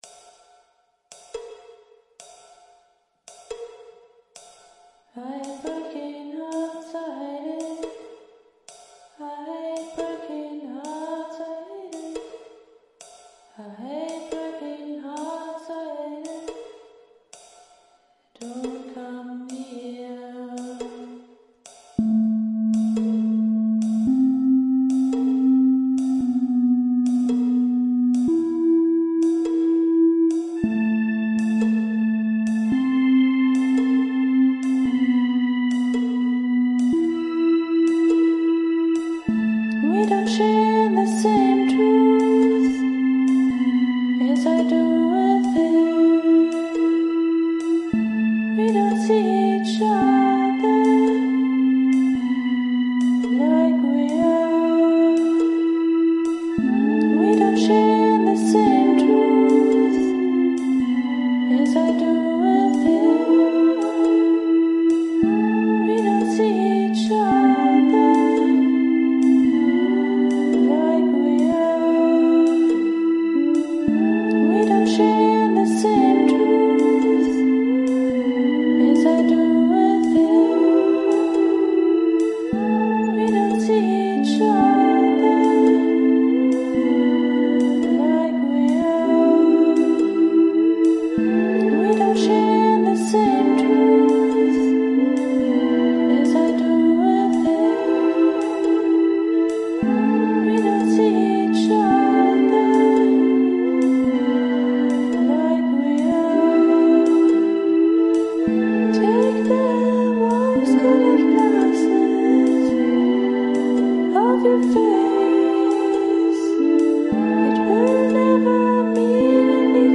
Es bleibt zwar sehr gleich, aber davon lebt die Stimmung ja auch irgendwie. Ein Rhythmuswechsel wäre eher störend, finde ich.